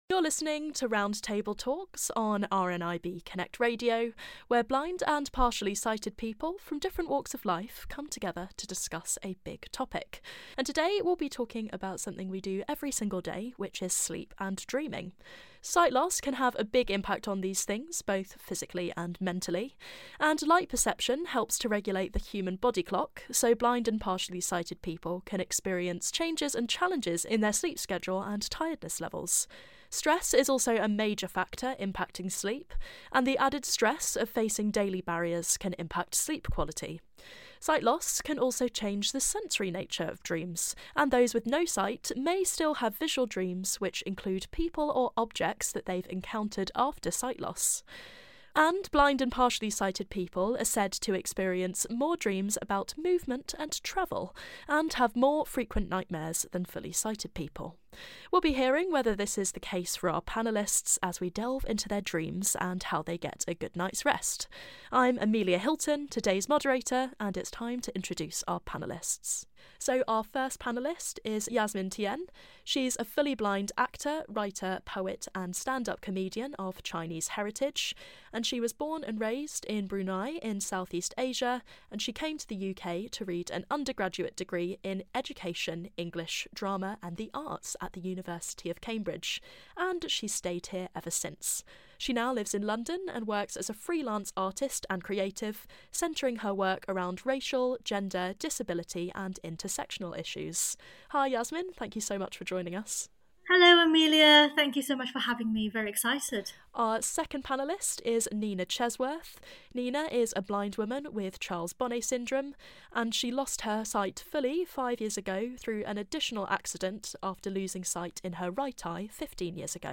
Sleep and Dreaming - Roundtable